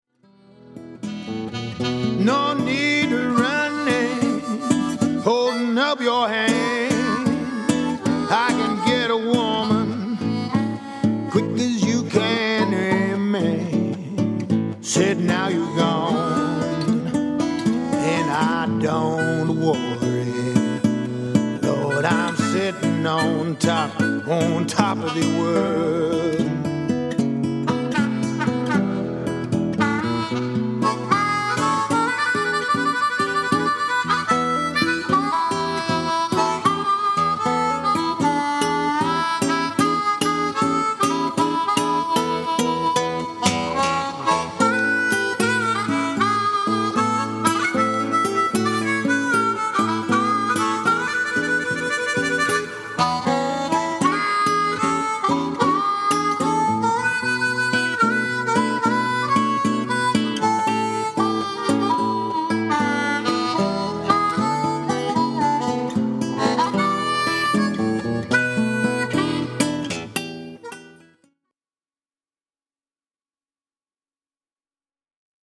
Recorded at Vincent Productions, Clarksdale Mississippi